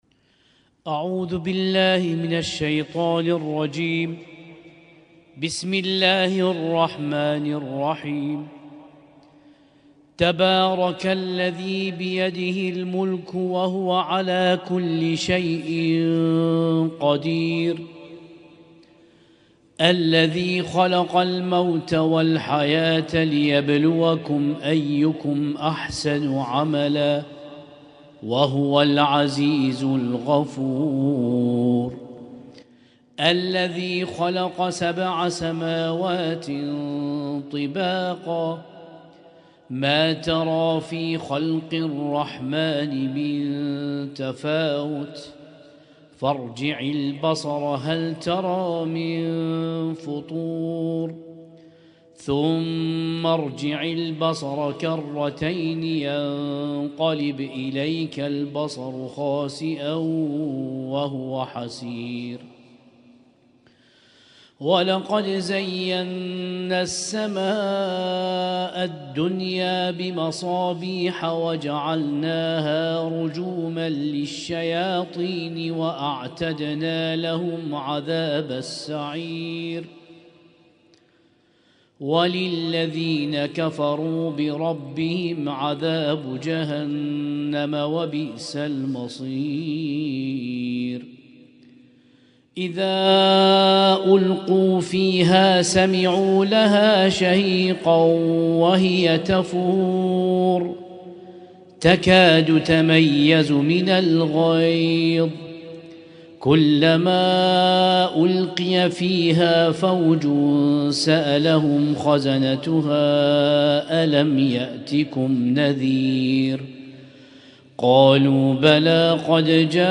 Husainyt Alnoor Rumaithiya Kuwait
اسم التصنيف: المـكتبة الصــوتيه >> القرآن الكريم >> القرآن الكريم - شهر رمضان 1446